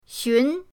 xun2.mp3